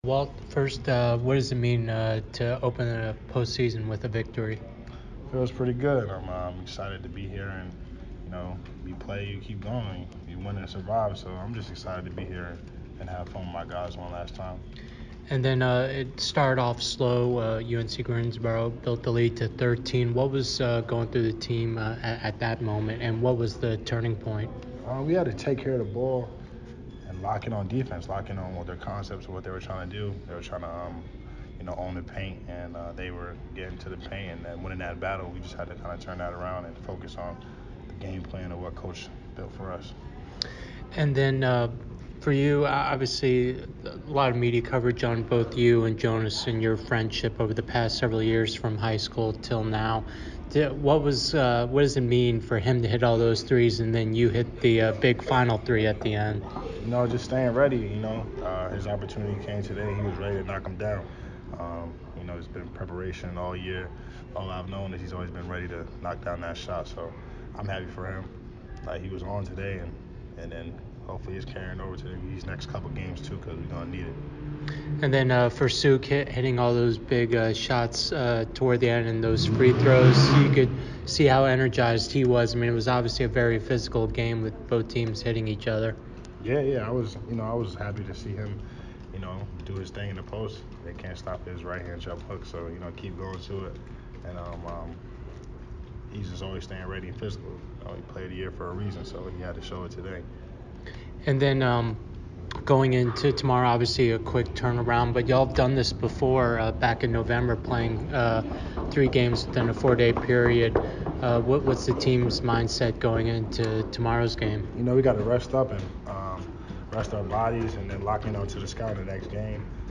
UNCG MBB Postgame Interview (3-20-22)